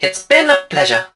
barley_die_01.ogg